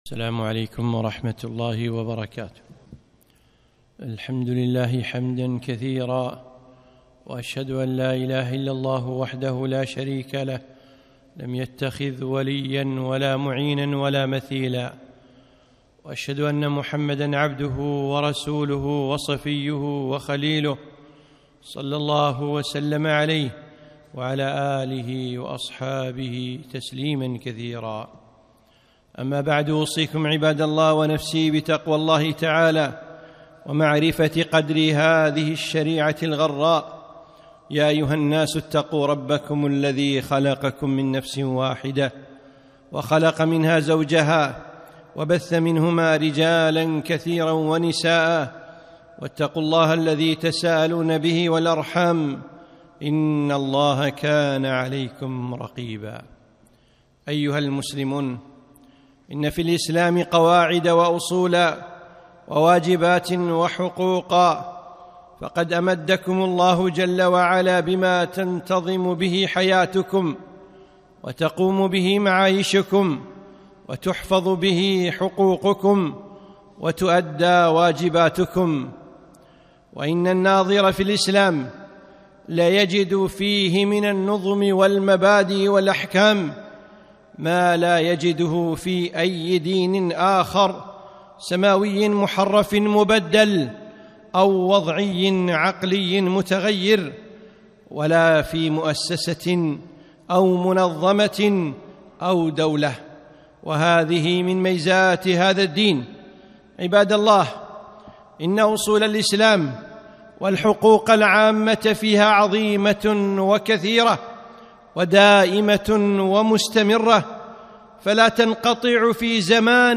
خطبة - الحقوق العامة في الإسلام